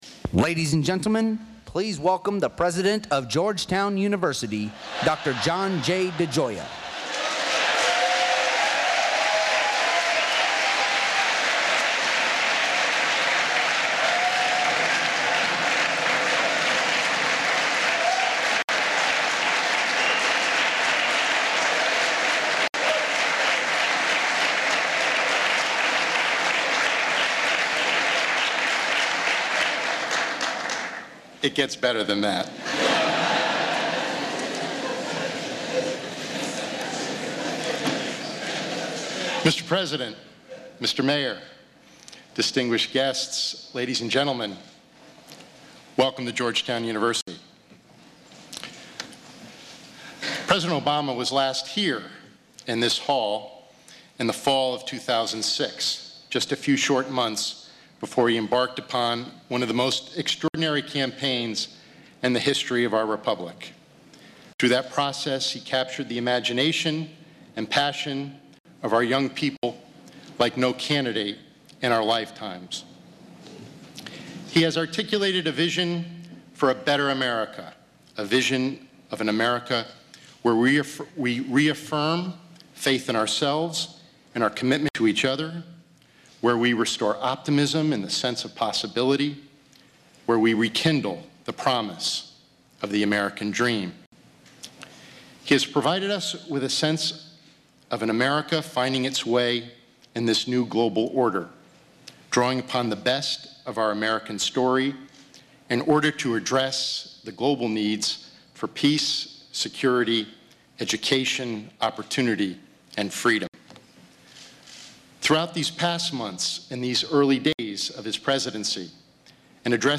U.S. President Barack Obama gives his State of the Economy speech at Georgetown University